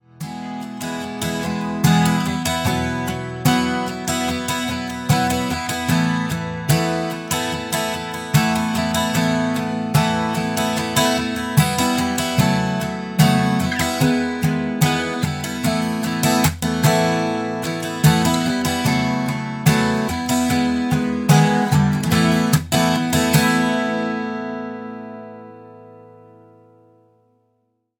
gitara akustyczna